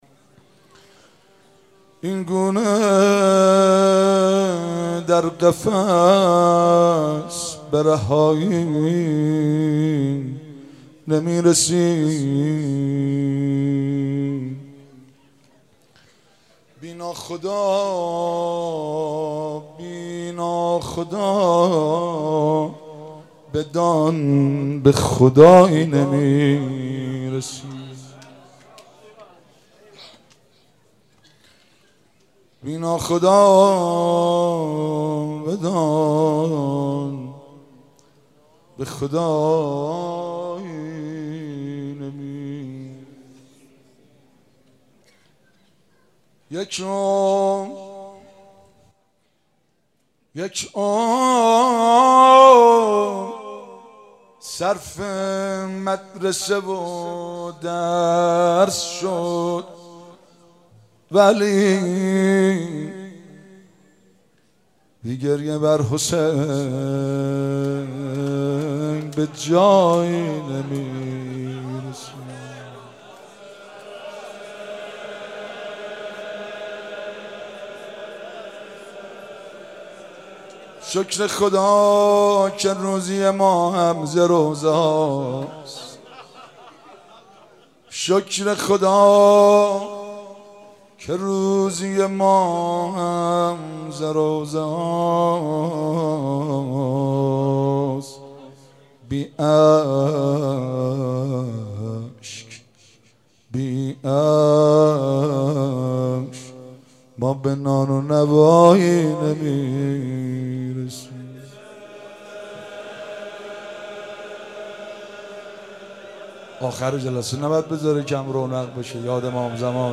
شب ششم محرم 95_شعر خوانی و مناجات